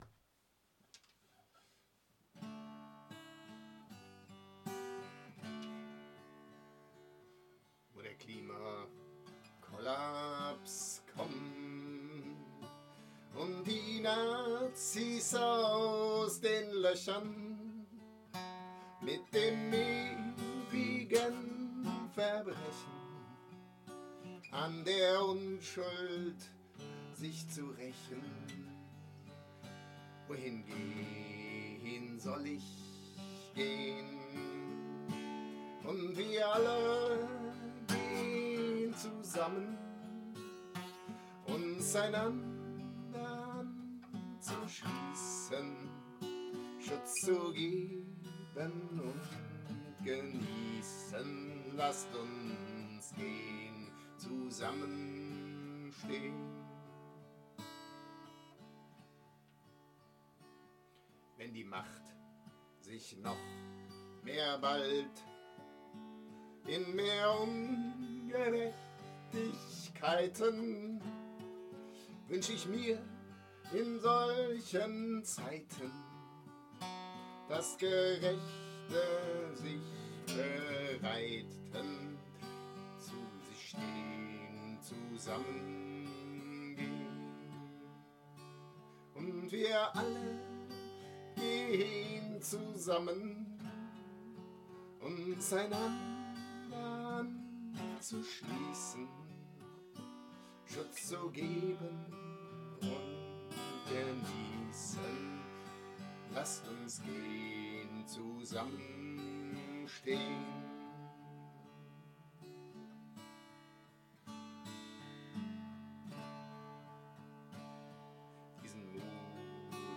Die Melodie ist eine alte, traditionelle, Schottische.